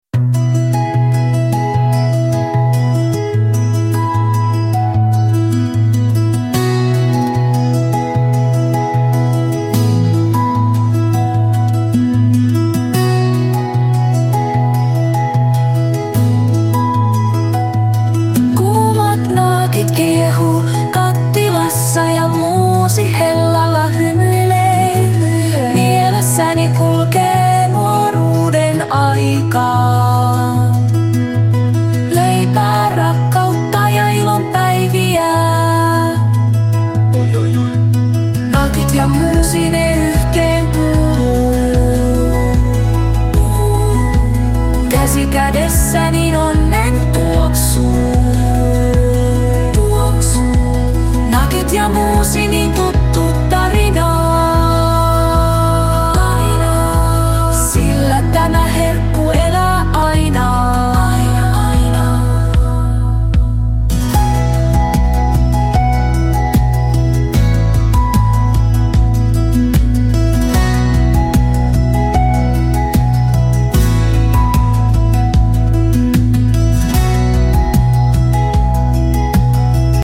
Tekoälyllä tehdyn musiikin laatu on kuitenkin vielä hivenen alhainen, joten sen käyttömahdollisuudet ovat vielä melko rajalliset.